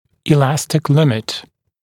[ɪ’læstɪk’lɪmɪt][и’лэстик ‘лимит]предел упругости